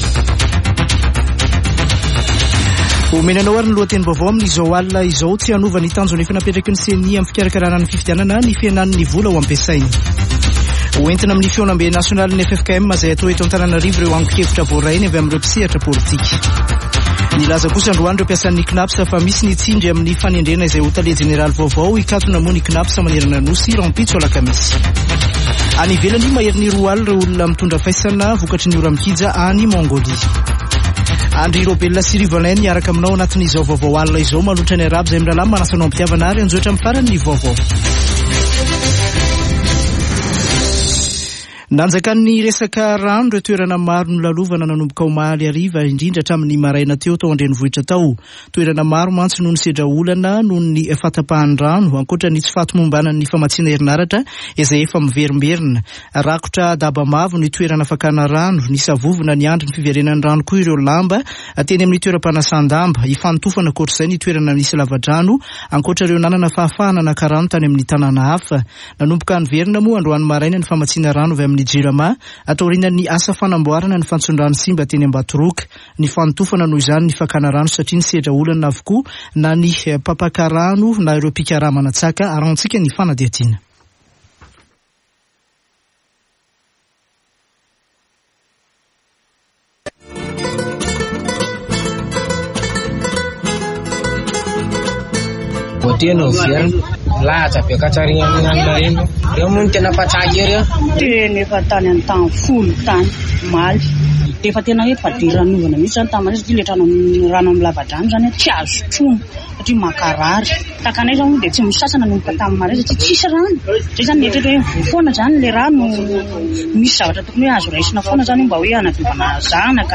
[Vaovao hariva] Alarobia 12 jolay 2023